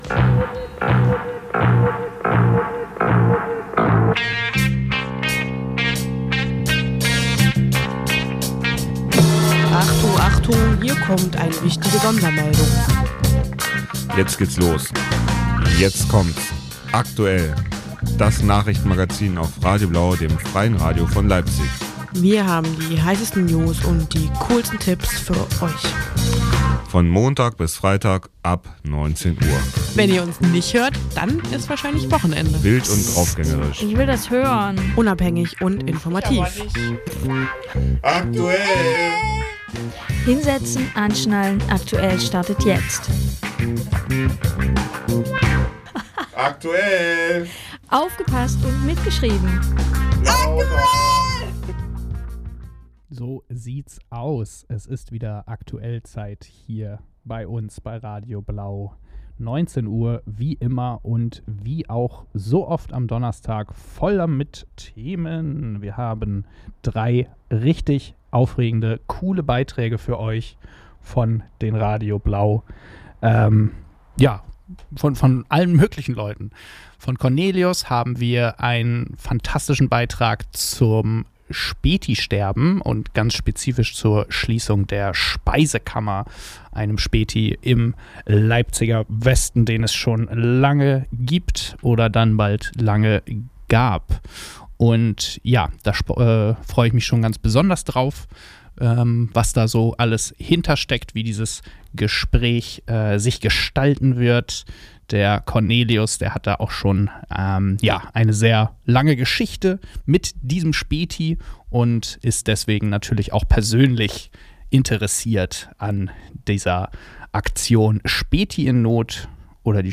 Juli 2025 Das wochentägliche Magazin am Abend, heute u.a. mit einem Beitrag zur Speisekammer , einem Späti im Leipziger Westen, der leider schließen musste und unsere Hilfe braucht.